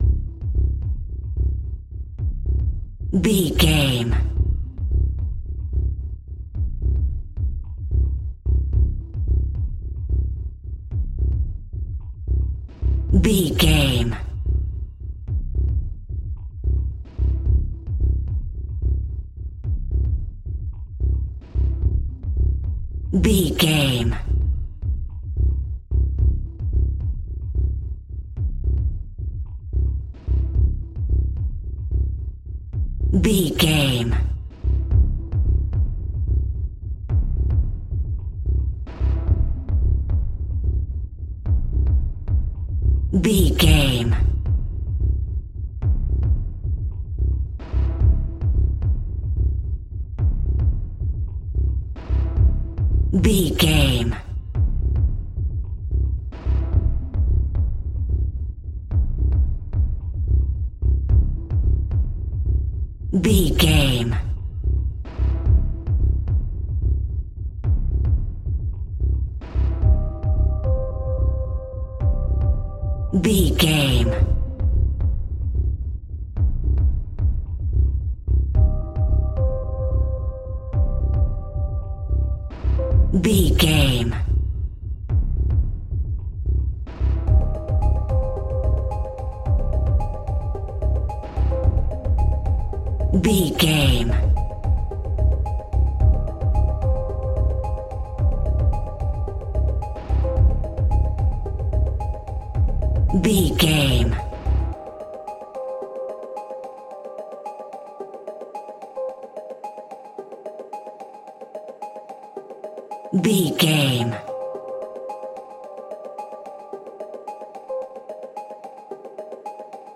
Suspense an Tension Music Bed.
Aeolian/Minor
scary
ominous
dark
haunting
eerie
percussion
synthesizer
instrumentals
mysterious